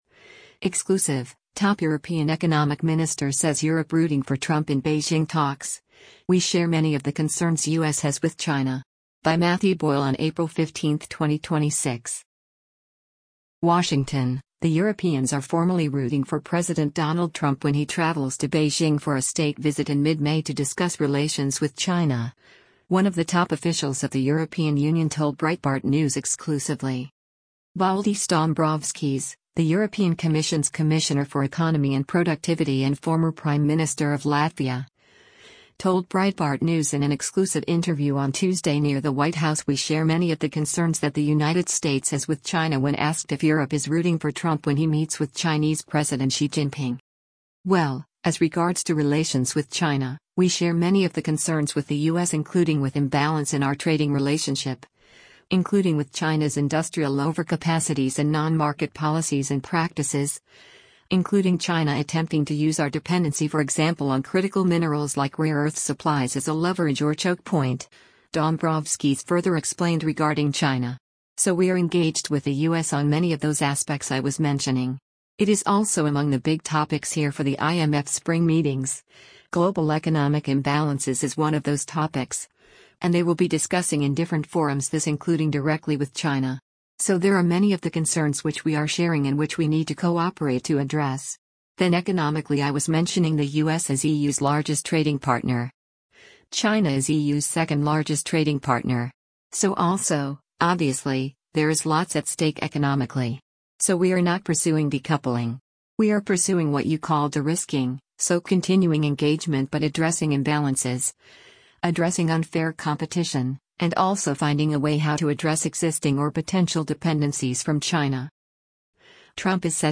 Valdis Dombrovskis, the European Commission’s Commissioner for Economy and Productivity and former Prime Minister of Latvia, told Breitbart News in an exclusive interview on Tuesday near the White House “we share many of the concerns” that the United States has with China when asked if Europe is rooting for Trump when he meets with Chinese President Xi Jinping.